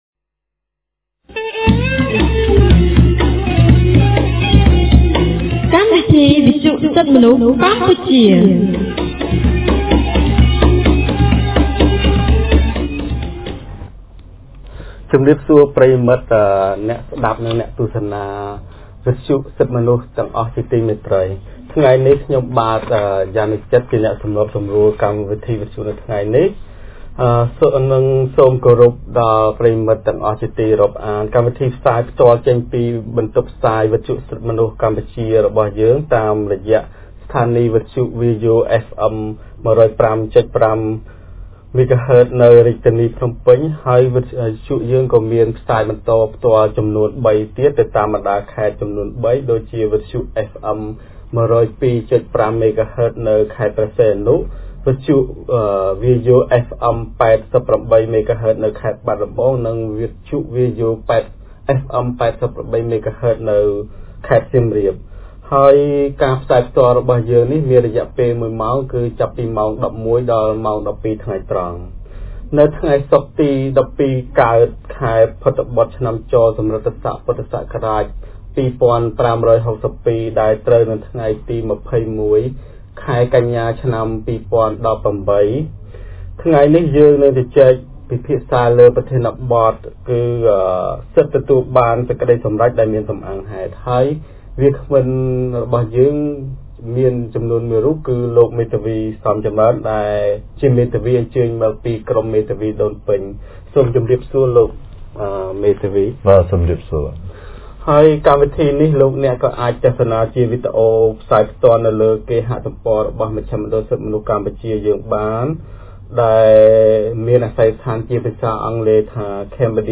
On 21 September 2018, CCHR’s Fair Trial Rights Project (FTRP) held a radio program with a topic on Right to a Reasoned Judgement.